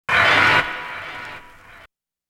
Def Hit.wav